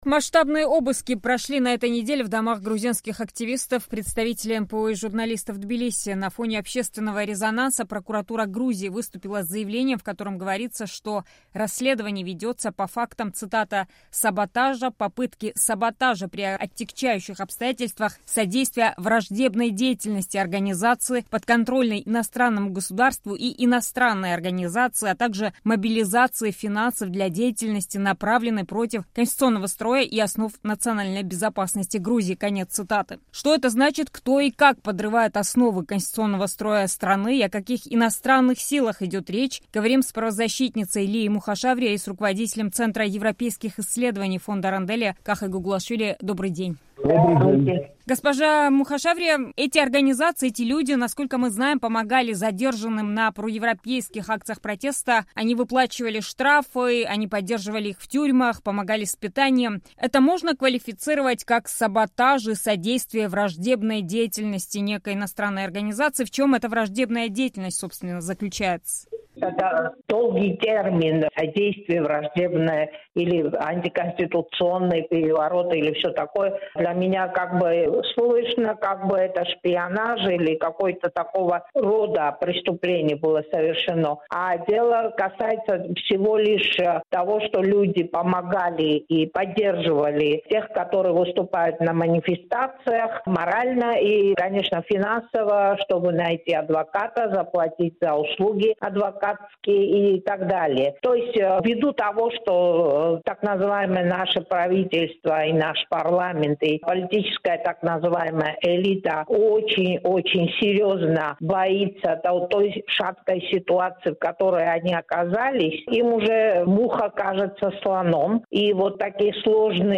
Рубрика Некруглый стол, разговор с экспертами на самые актуальные темы